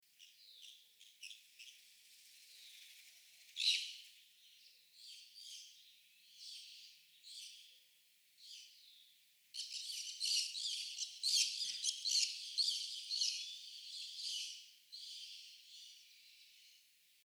Chiripepé Cabeza Parda (Pyrrhura molinae)
4 individuos
Nombre en inglés: Green-cheeked Parakeet
Condición: Silvestre
Certeza: Fotografiada, Vocalización Grabada